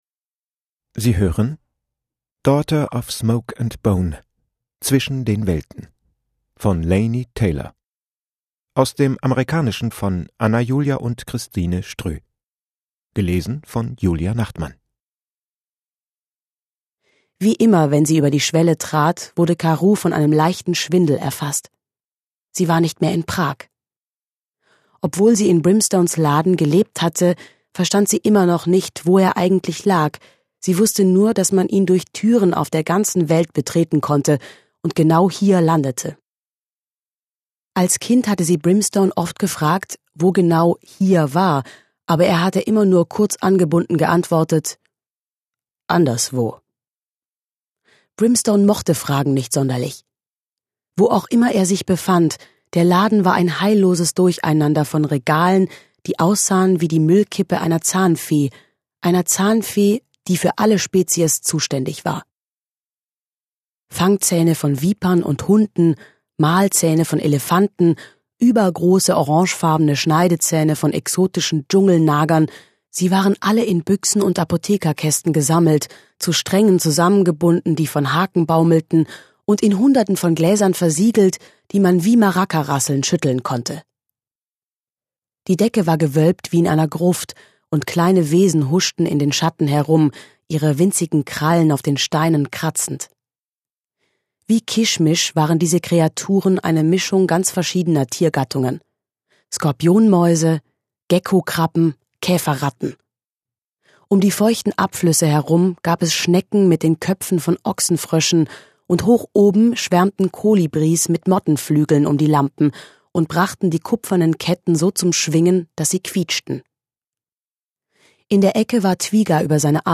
Daughter of Smoke and Bone - Laini Taylor | argon hörbuch